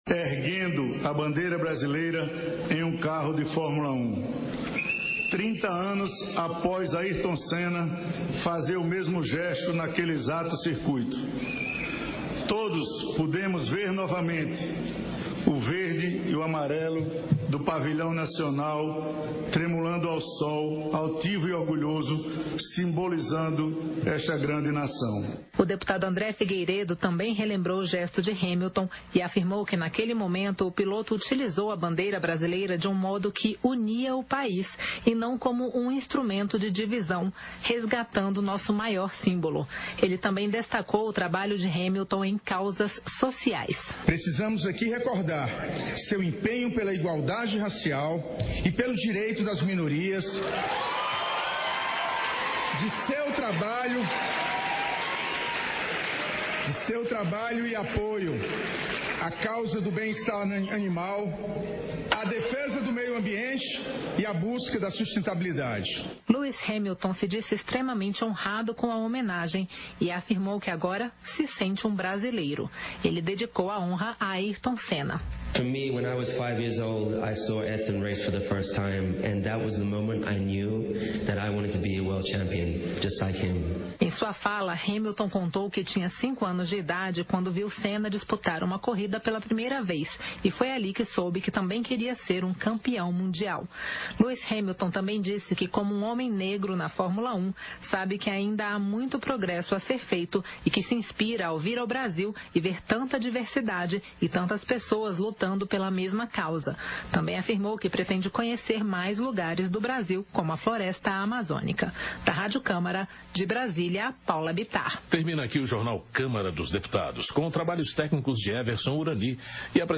Sessão Ordinária 36/2022